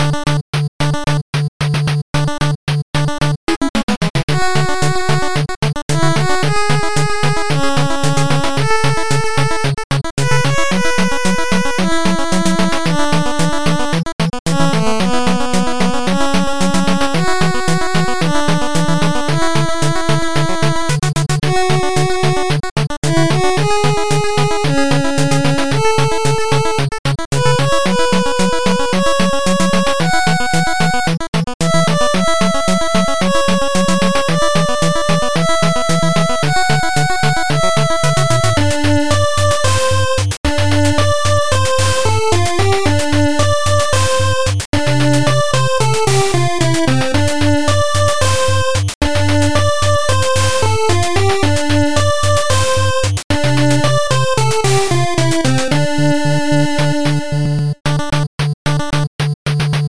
8bit music for ending.